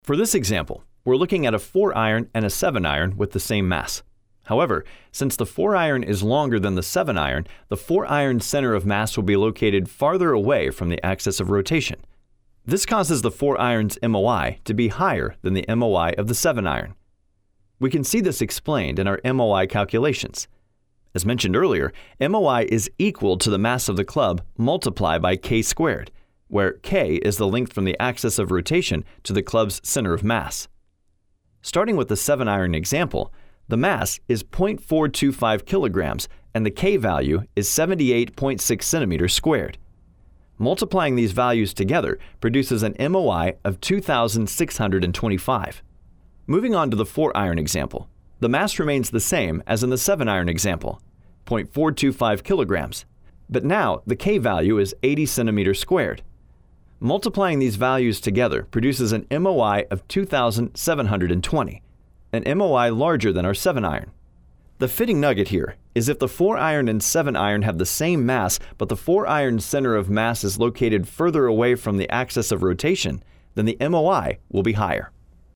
Middle Aged
Versatile , Unique , Relaxed , the guy next door , energetic , Intuitive , Adaptable , Expressive , Dynamic , Quick turn around , Takes direction very well!